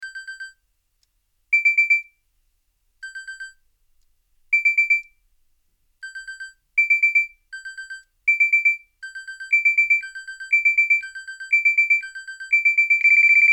clock-alarm.mp3